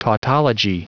Prononciation du mot tautology en anglais (fichier audio)
Prononciation du mot : tautology